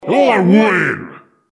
This is an audio clip from the game Team Fortress 2 .
Category:Saxton Hale audio responses